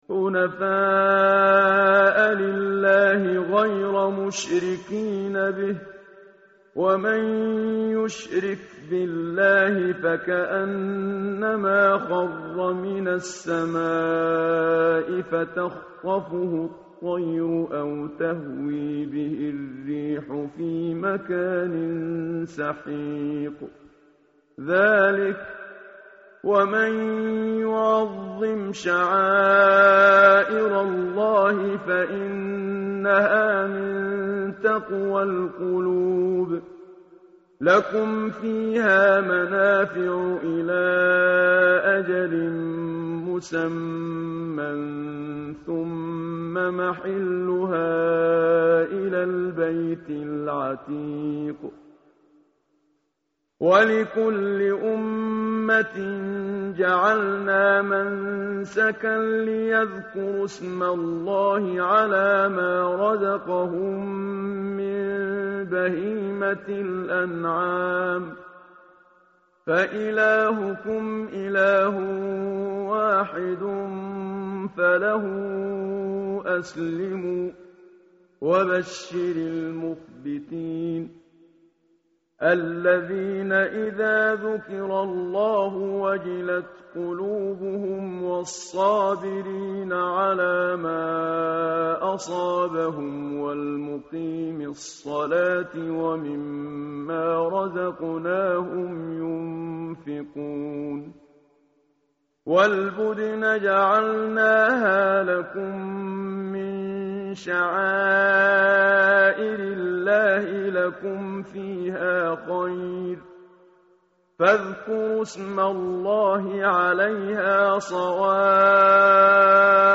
tartil_menshavi_page_336.mp3